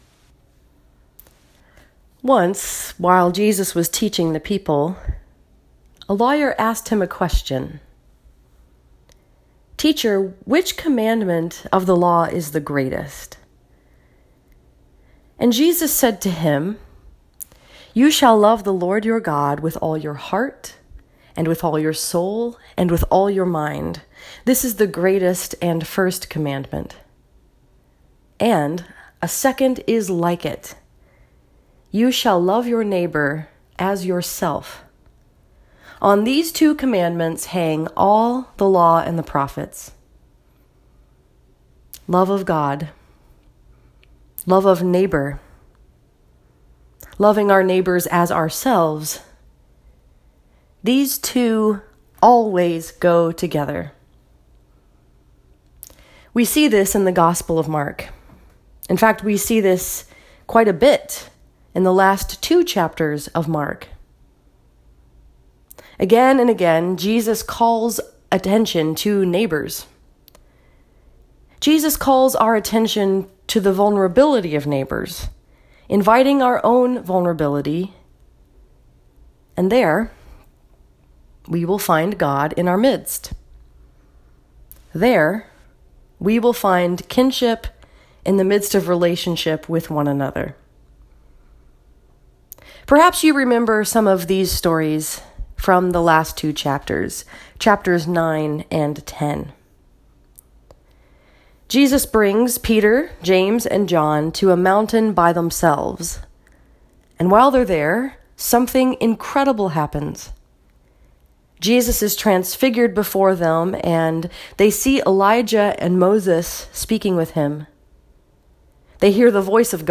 This sermon was preached at First Presbyterian Church in Howell, Michigan and was focused upon the story that is told in Mark 10:17-31. The sermon also references Isaiah 49:8-23.